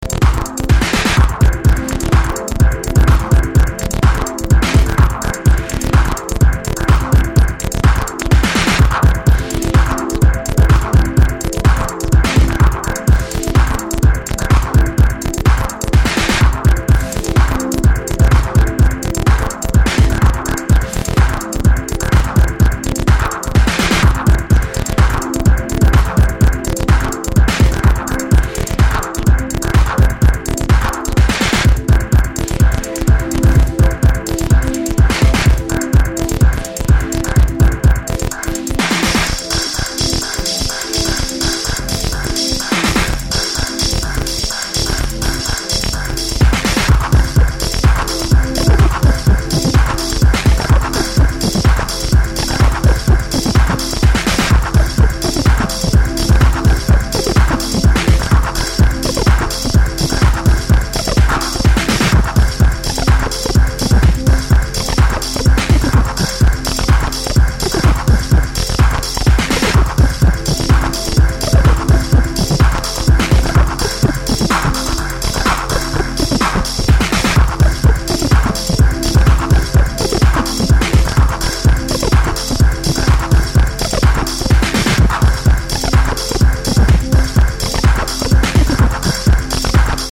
3 killer hardware cuts